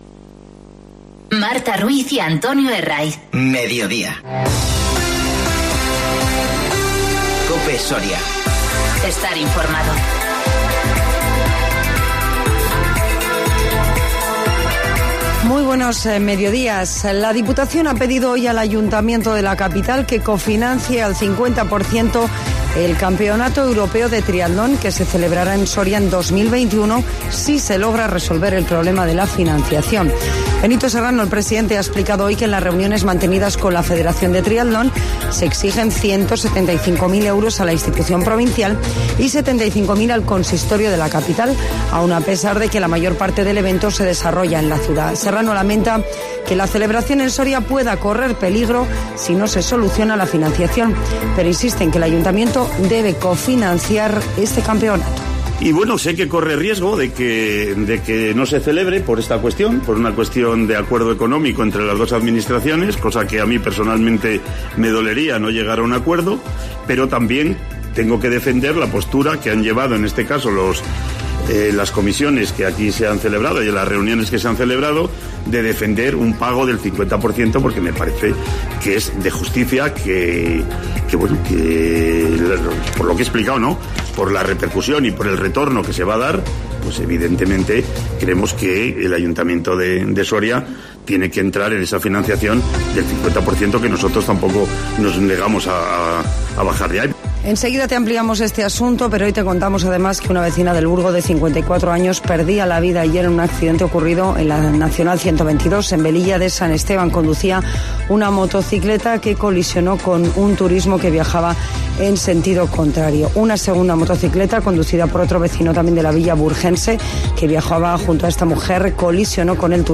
Informativo 17-02-20